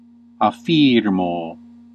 Ääntäminen
Classical: IPA: /afˈfir.moː/